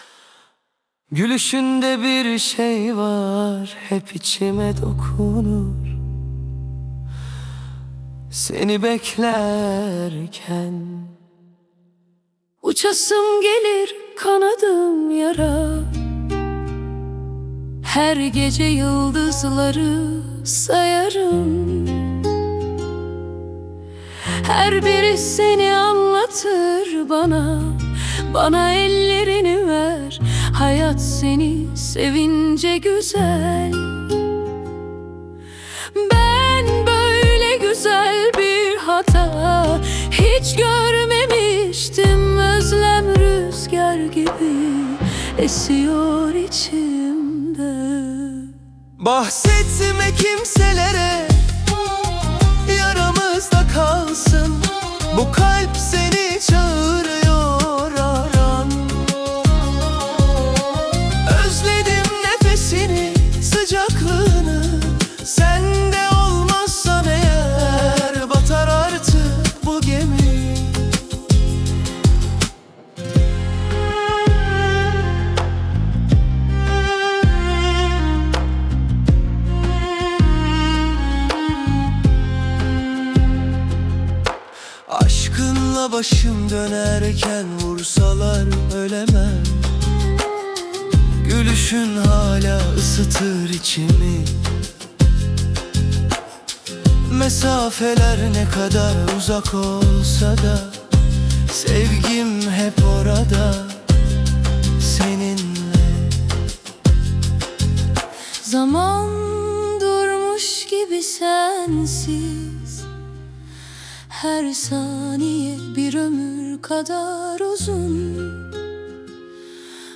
AI ile üretilen 58+ özgün müziği keşfedin
🎤 Vokalli 02.12.2025